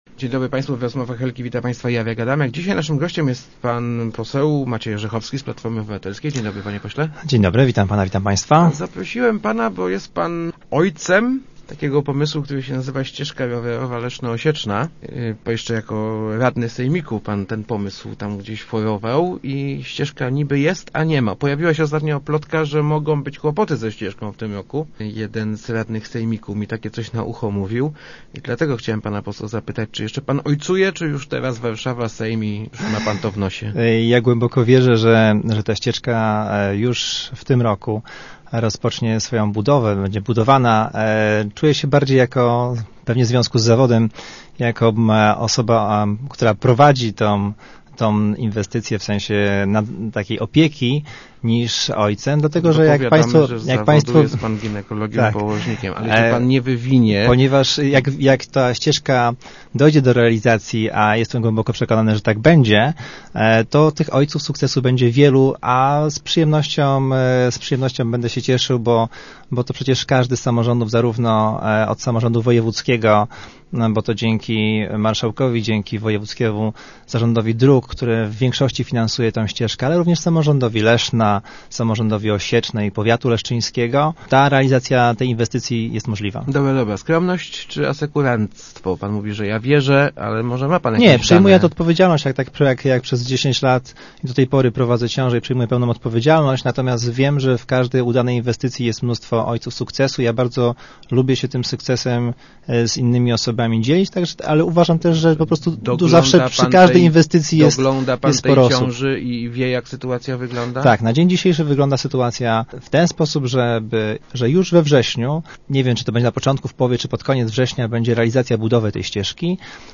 morzechowski80.jpgBudowa ścieżki rowerowej Leszno-Osieczna zacznie się we wrześniu – powiedział w Rozmowach Elki poseł PO Maciej Orzechowski. Zapewnił on, że nie ma żadnych zagrożeń dla tej inwestycji, nie zabraknie też na nią pieniędzy.